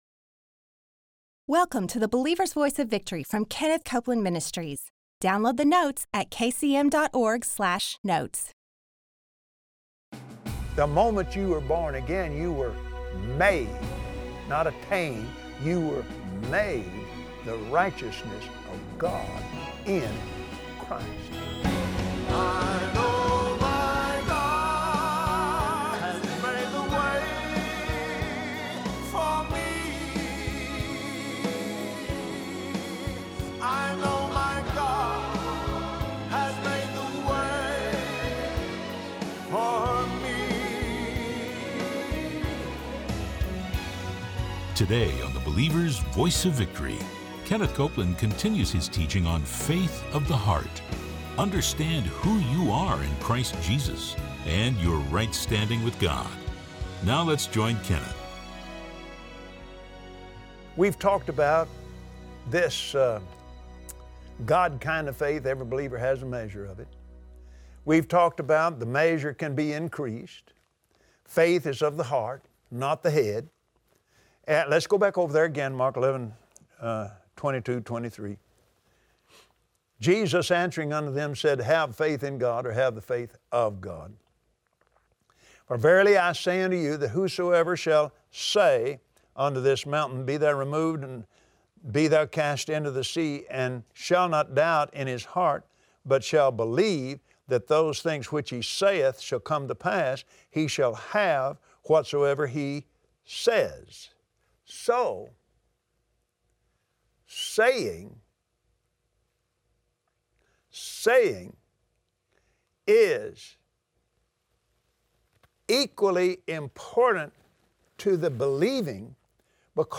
Believers Voice of Victory Audio Broadcast for Friday 03/31/2017 Watch Kenneth Copeland on the Believer’s Voice of Victory broadcast share how your right standing with God enables you to have victory over sin, sickness and disease.